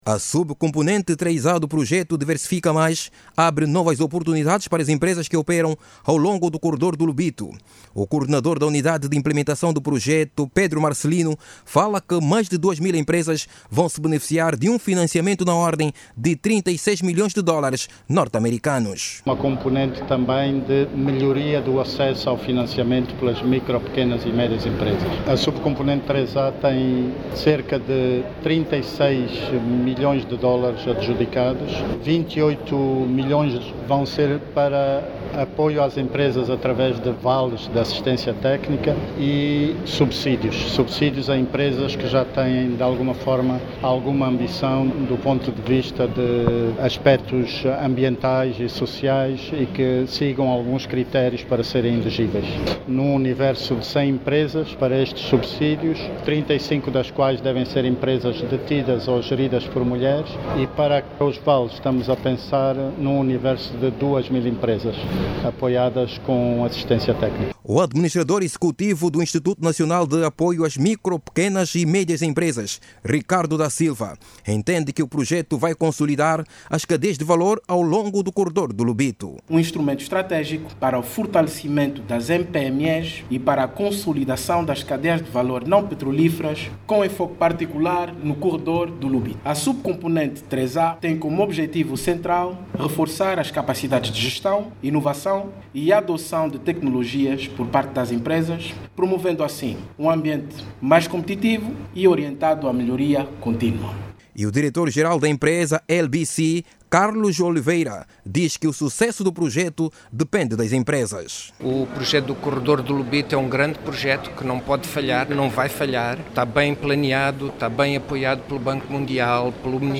Estes valores foram apresentados hoje, em Luanda, aos operadores económicos. Jornalista